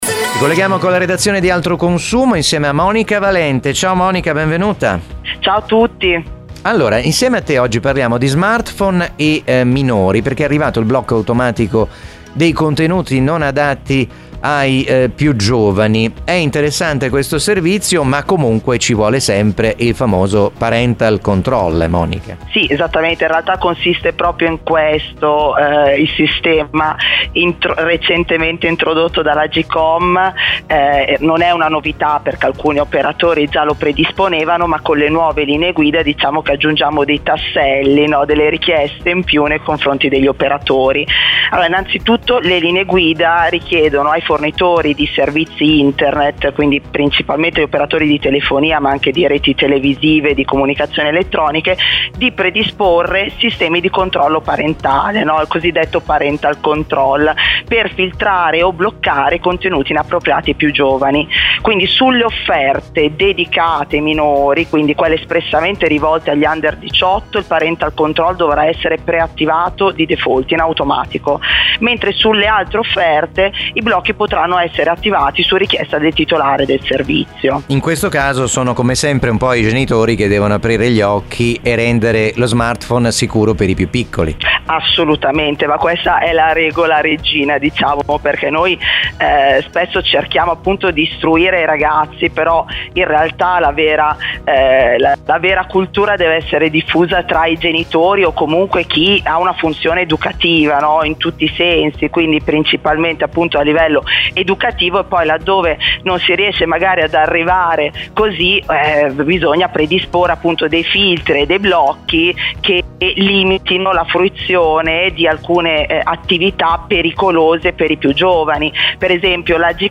Un talk show con ospiti illustri e tanti personaggi, giornalisti, opinionisti ed esperti.